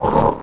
GRUNT1.mp3